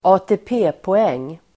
Uttal: [²a:te:p'e:poeng:]